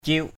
/ciʊʔ/ 1.